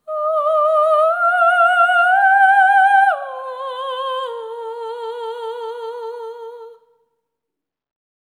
ETHEREAL06-R.wav